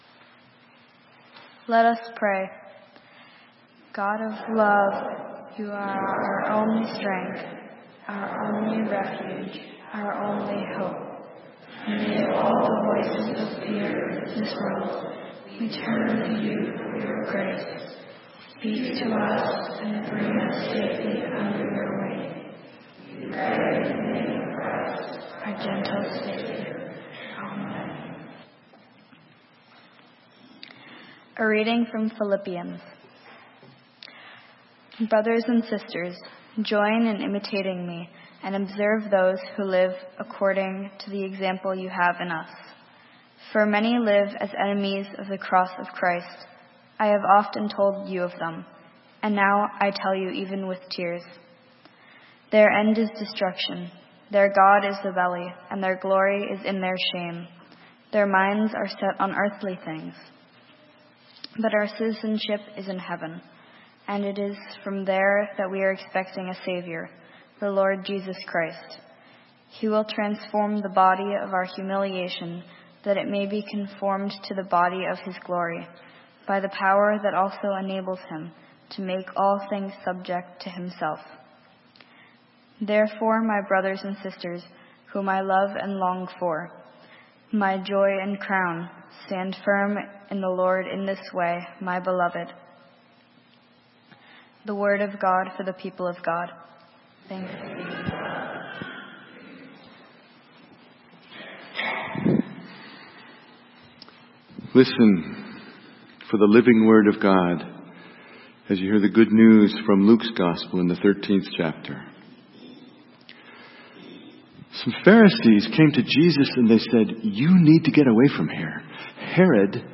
Sermon: Stand Firm - St. Matthew's UMC